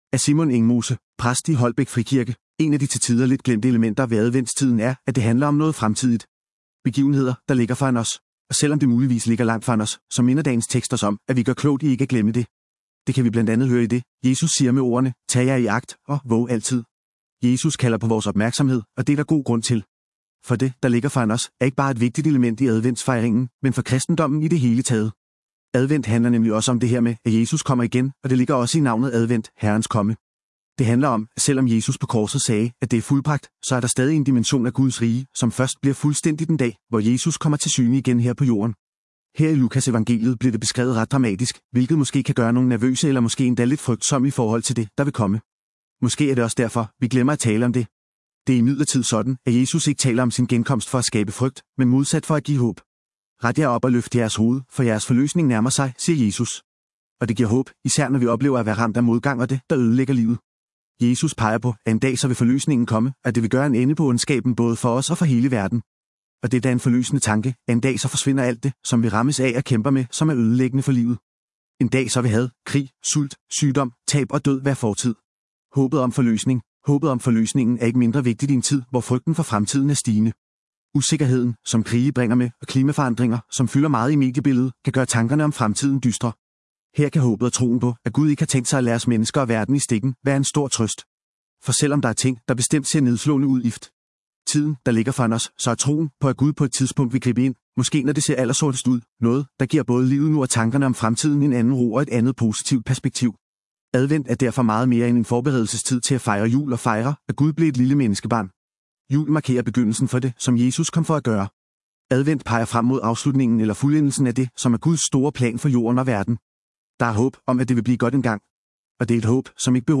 Ugens Prædiken